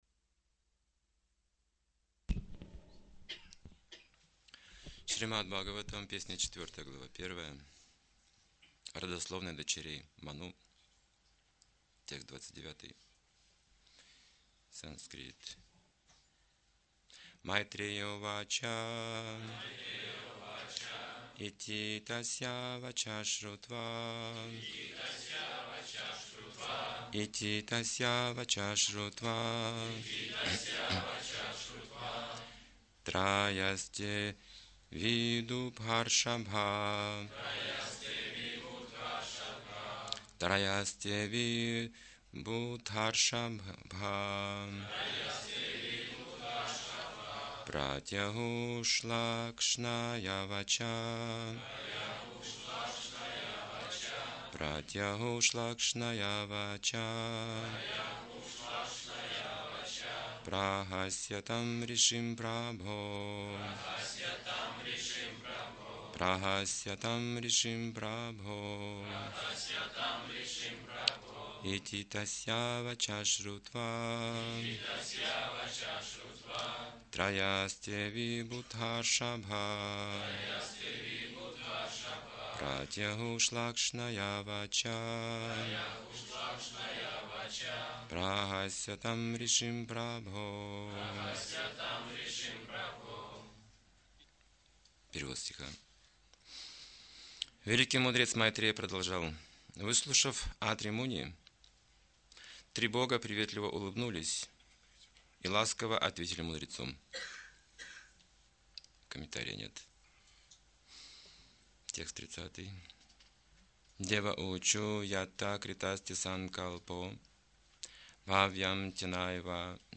Темы, затронутые в лекции: Реальность представляет желания человека ,а не его представления. Желания связанные с Кришной ,духовны. В чем особенность Сознания Кришны?